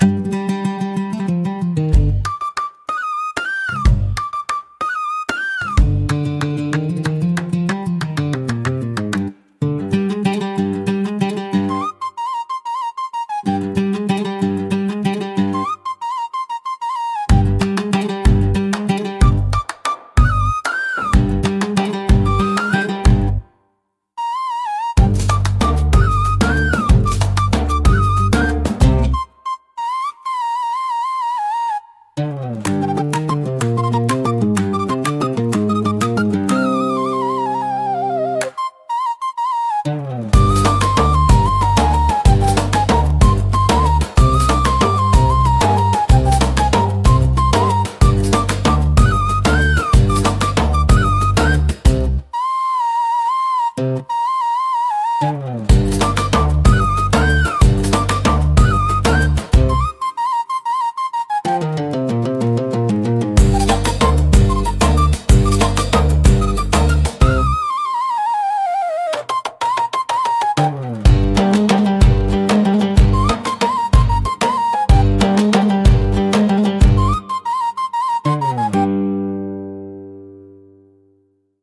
異国情緒を強調し、空間に奥行きと深みを加えるため、幻想的かつ情熱的な演出に効果的です。